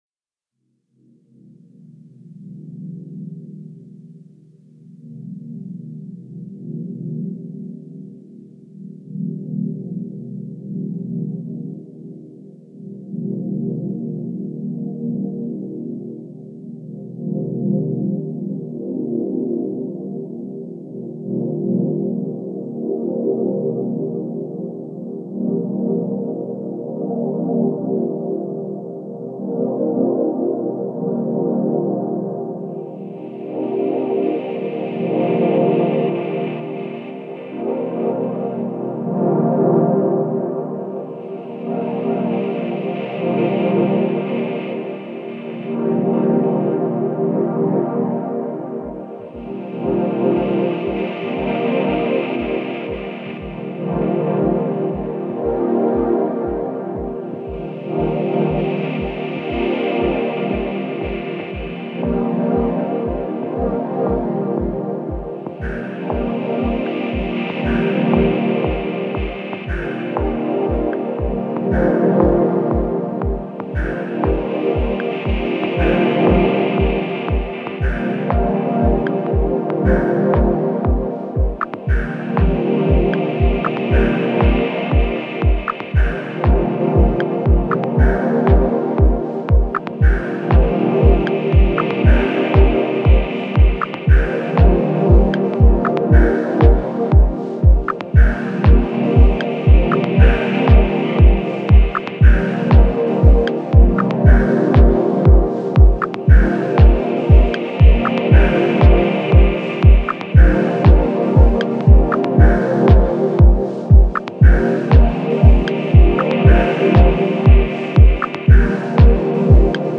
Genre: Dub Techno/Ambient.